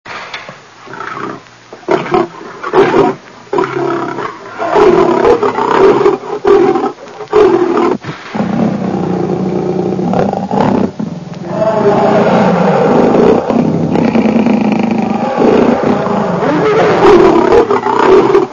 Pianeta Gratis - Audio - Animali
leoni_lions02.wav